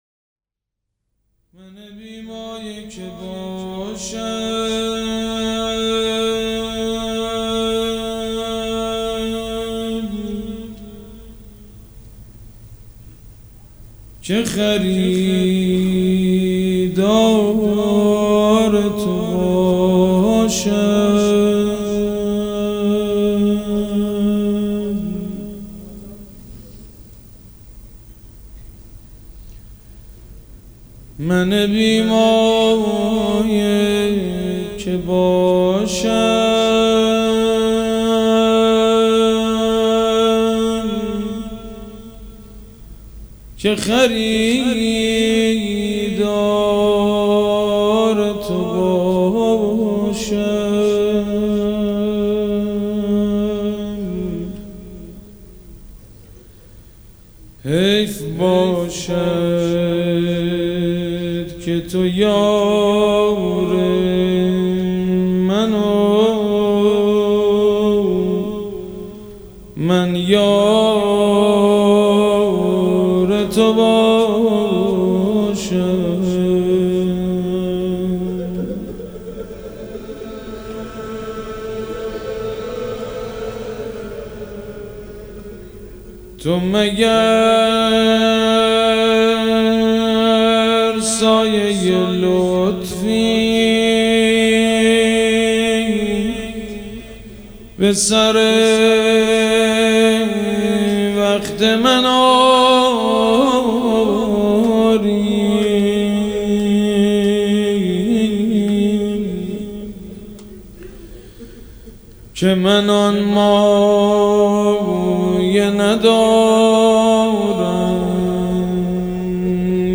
روضه
شهادت امام صادق علیه السلام سه شنبه 27 خرداد ماه 1399 حسینیه ی ریحانه الحسین (س)